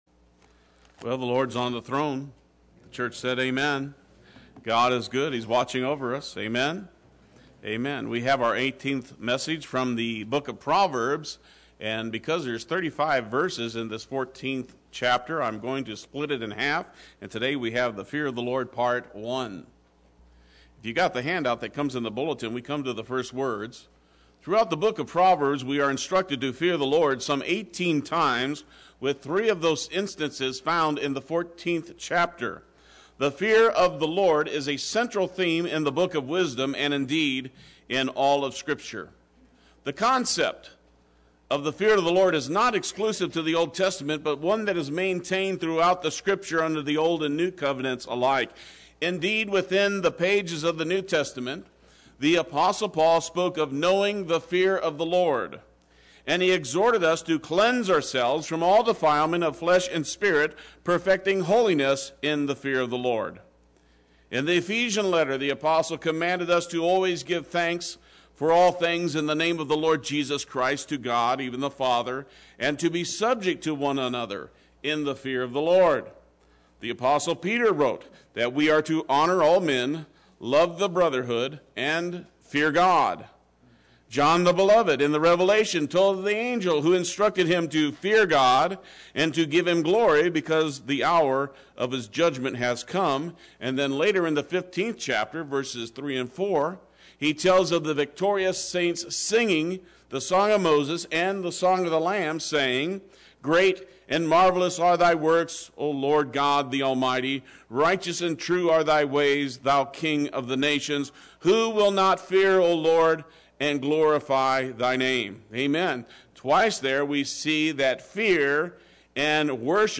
Play Sermon Get HCF Teaching Automatically.
Part 1 Sunday Worship